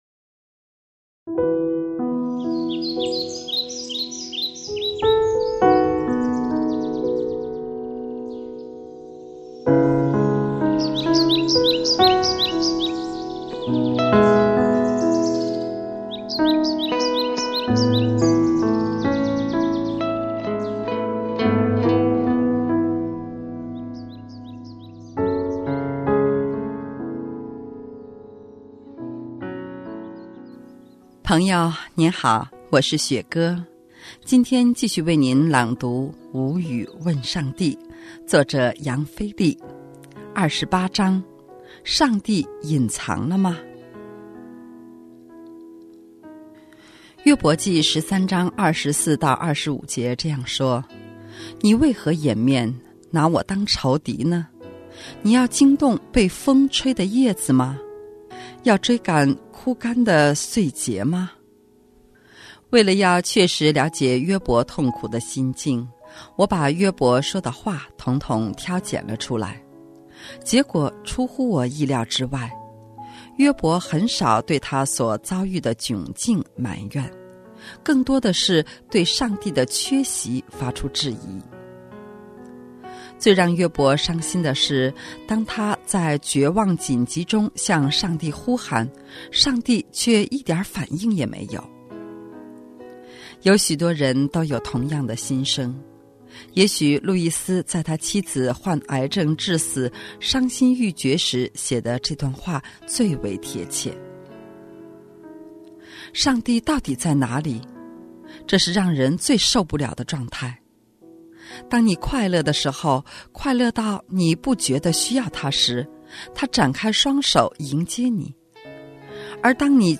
今天继续为你朗读《无语问上帝》。